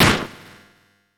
Super Smash Bros. game sound effects
Fan Smack Hit.wav